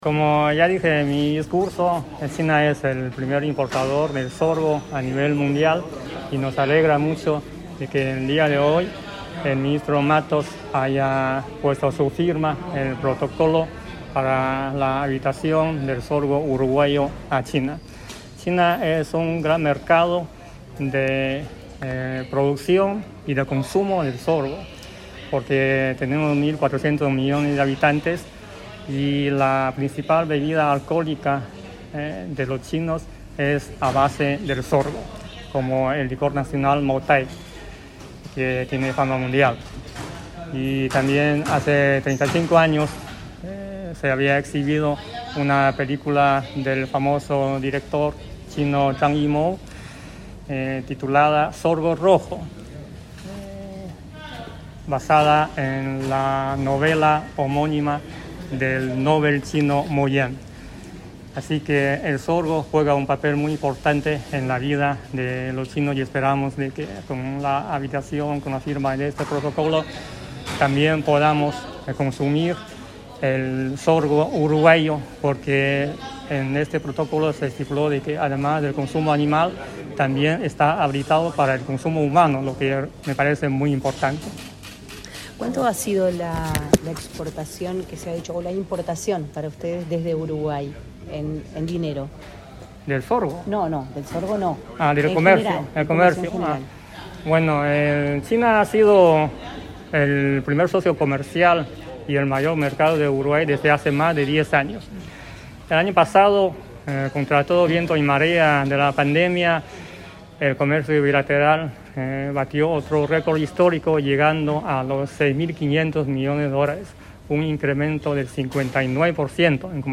Declaraciones a la prensa del embajador de China en Uruguay, Wang Gang
Luego el diplomático dialogó con la prensa.